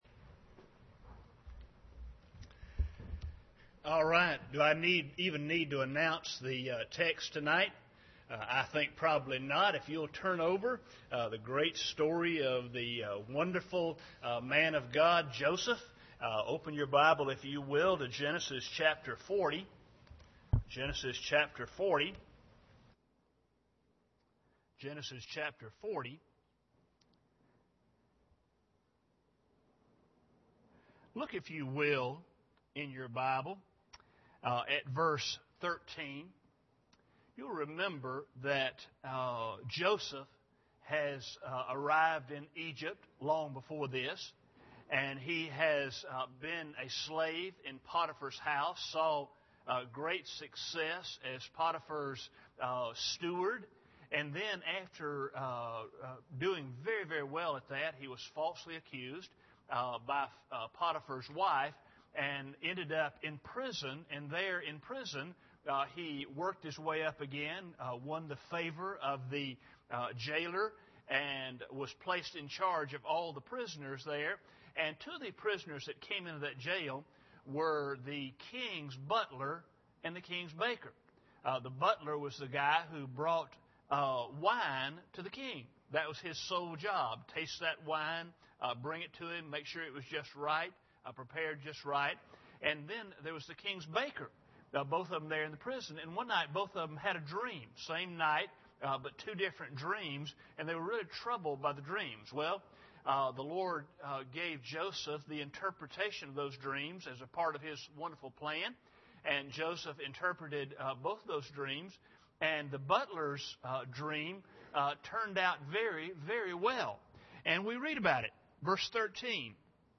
Genesis 40:13-15 Service Type: Sunday Evening Bible Text